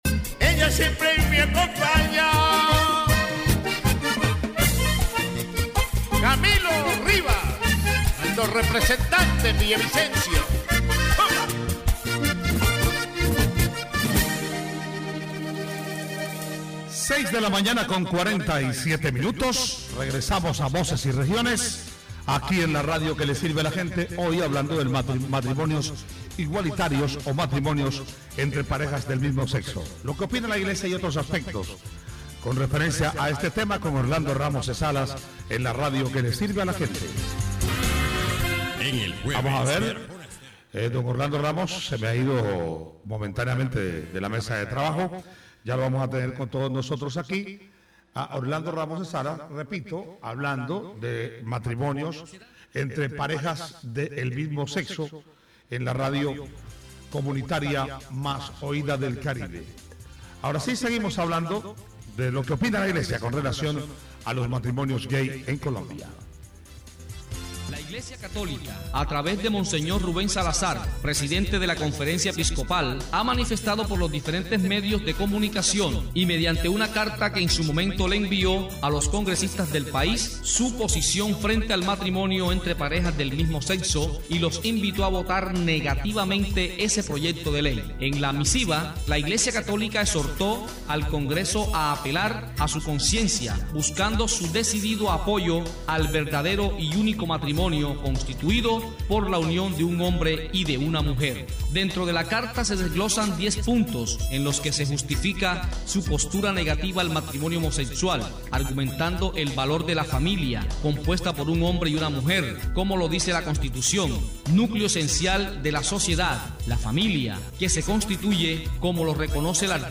The interview highlights the rights gained by the LGBTIQ+ community with marriage legalization and the existing barriers in adoption and legal recognition. Reactions from different sectors, including the church, political parties, and civil society, are also addressed.